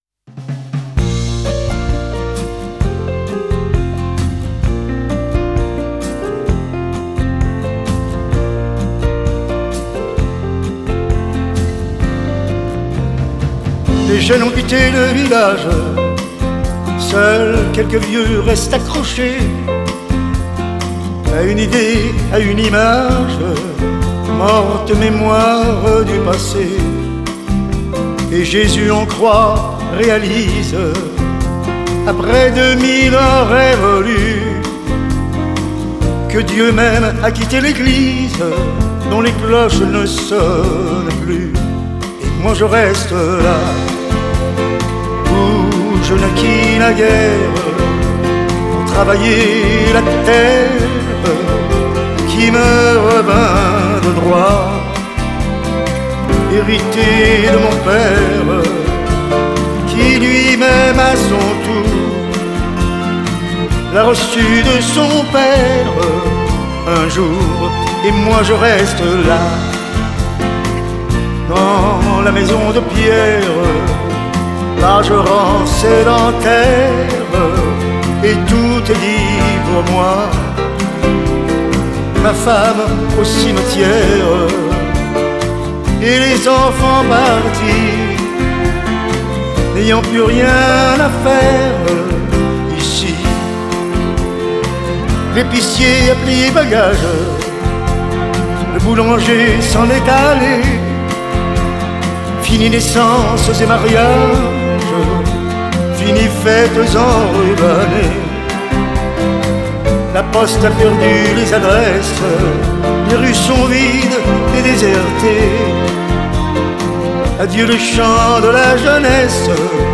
Genre: Chanson